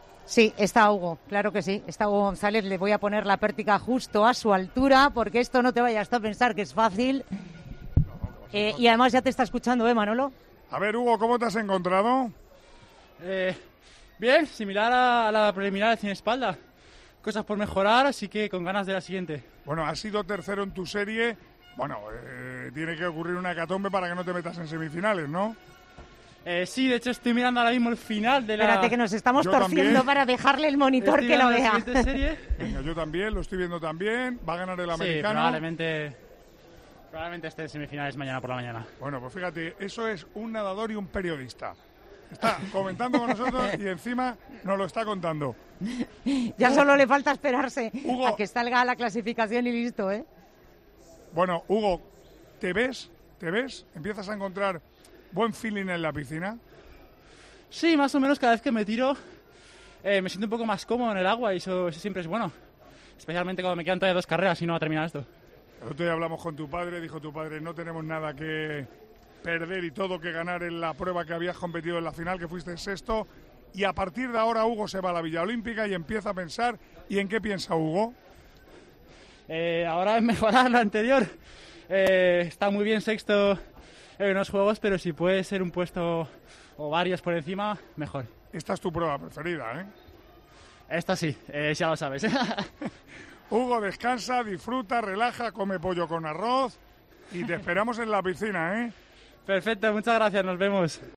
El nadador español, que ha conseguido una plaza para las semifinales de 200 estilos, atendió a los micrófonos de la COPE, donde mostró su positividad tras la prueba.